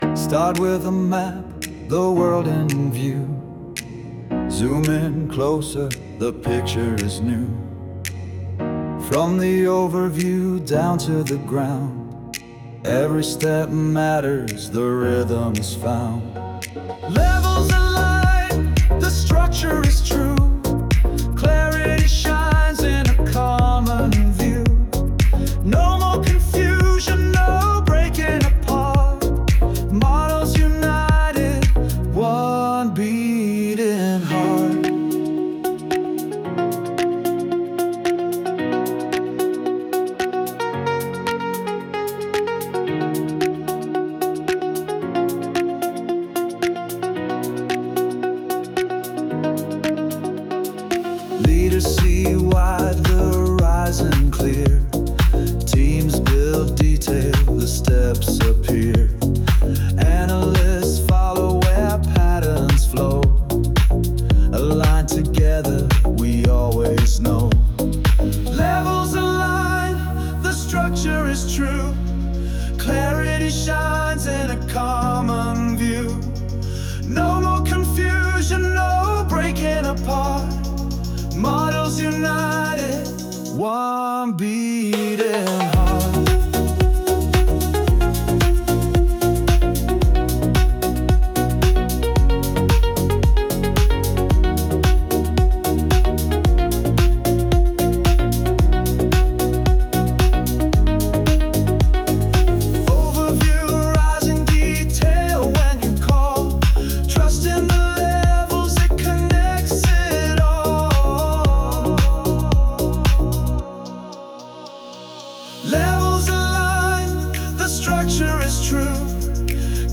Tropical House · 112 BPM · Eng